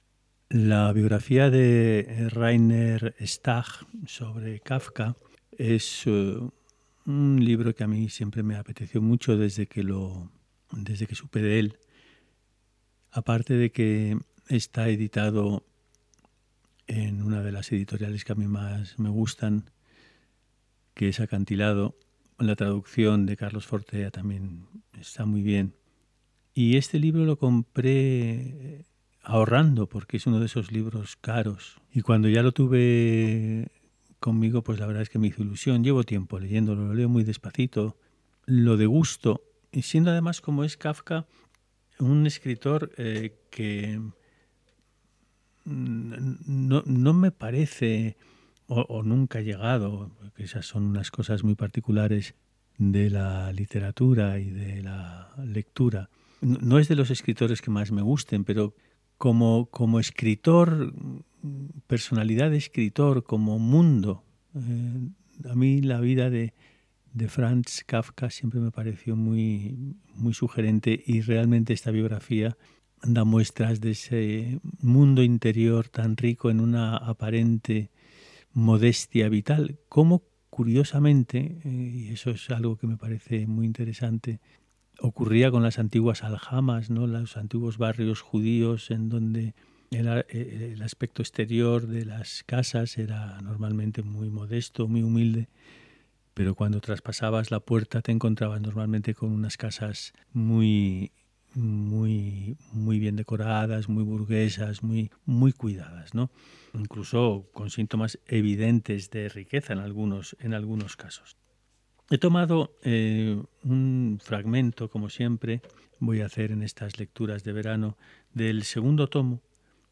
Lecturas en alta voz